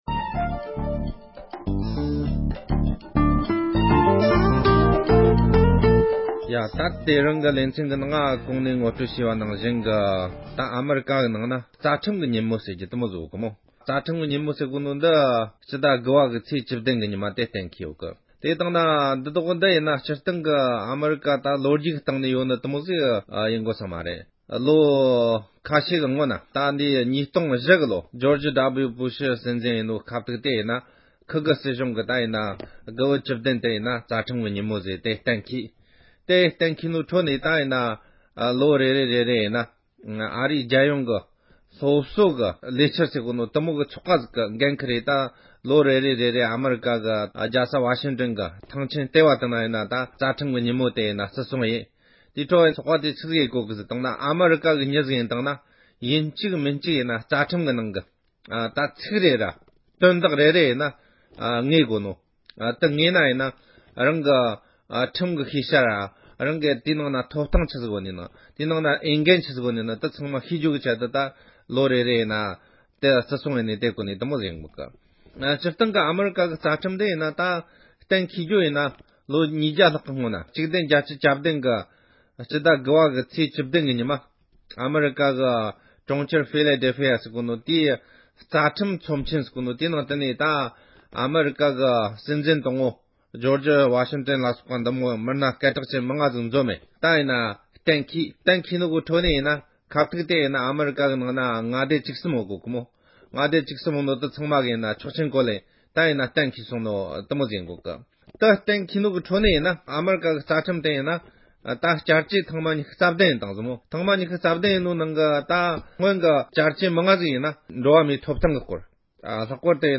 བོད་མི་ཚོས་ཁྲིམས་ལུཊ་ནང་རང་ཉིད་ལ་ཐོབ་ཐང་གང་འདྲ་ཡོད་པའི་ཐོག་དོ་སྣང་བྱ་རྒྱུར་གལ་ཆེན་པོ་ཡིན་པའི་སྐོར་དཔྱད་གཏམ།